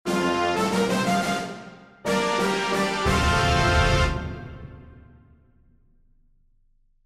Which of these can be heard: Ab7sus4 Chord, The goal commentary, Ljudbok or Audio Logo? Audio Logo